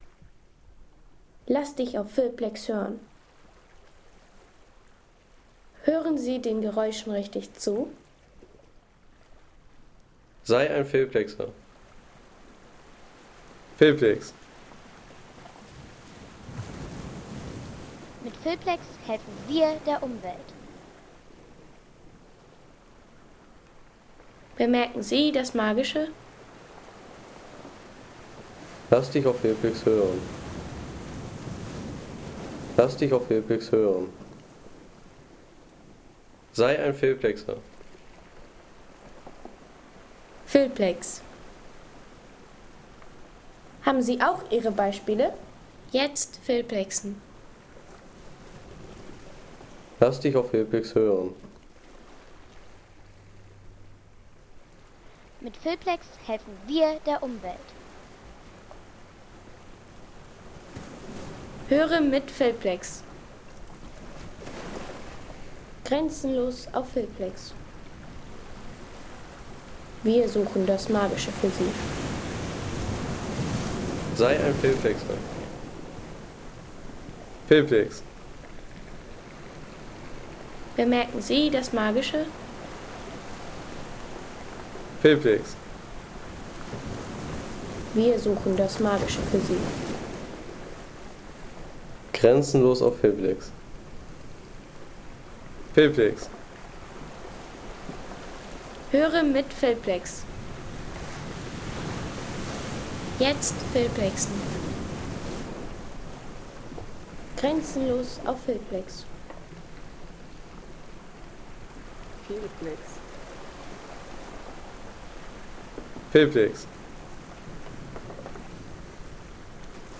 Steiniger Strand von Gran Canaria
Das Spiel der Wellen am steinigen Strand von Gran Canaria.